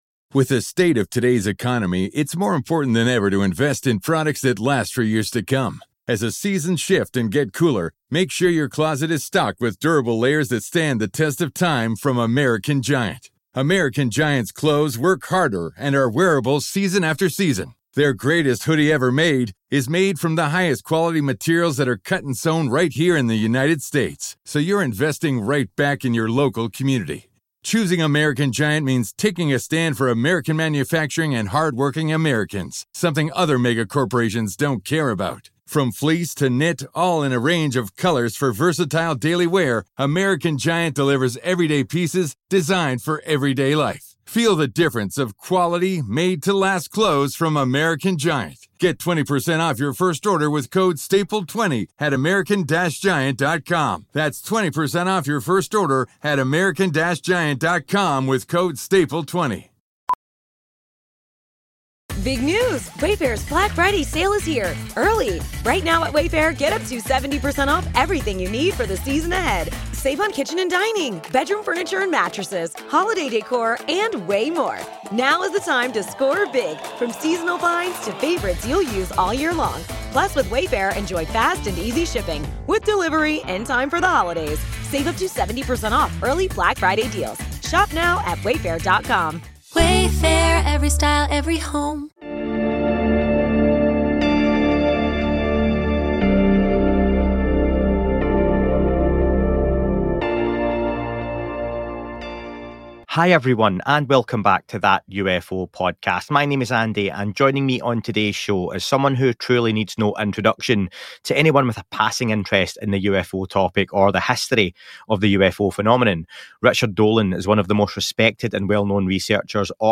Listener questions cover everything from historical UFO sightings in China to the complexities of abduction experiences. A comprehensive and engaging discussion for anyone fascinated by the UFO phenomenon and its wider implications.